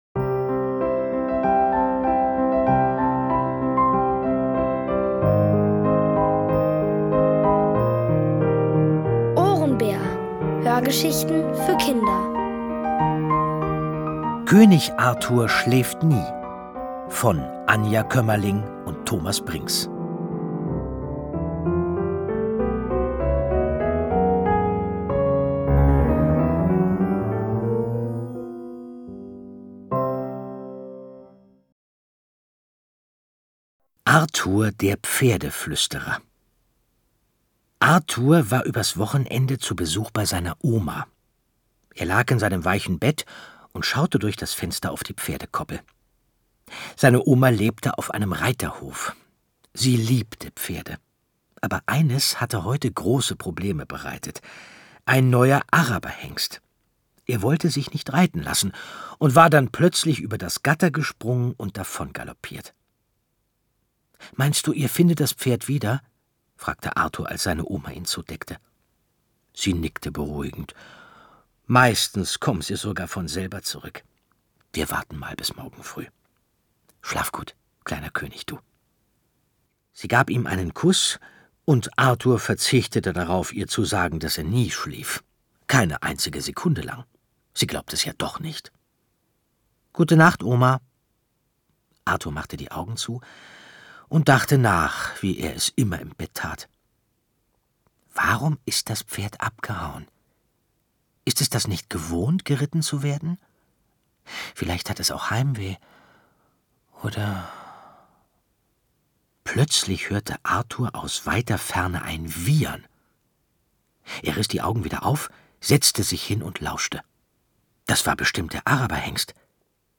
Von Autoren extra für die Reihe geschrieben und von bekannten Schauspielern gelesen.
Es liest: Andreas Fröhlich.